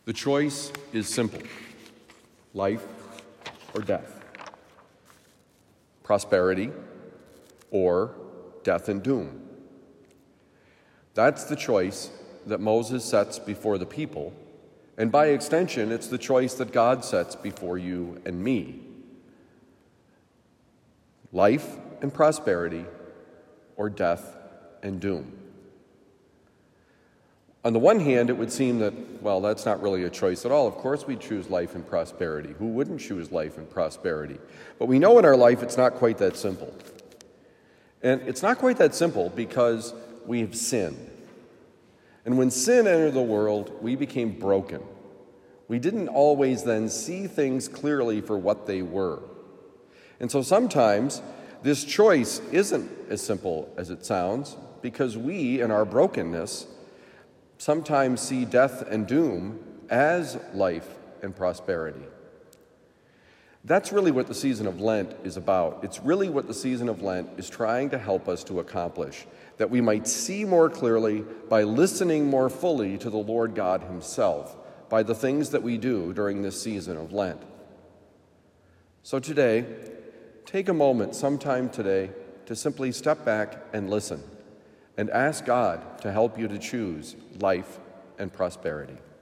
An Easy Choice: Homily for Thursday, February 23, 2023
Given at Christian Brothers College High School, Town and Country, Missouri.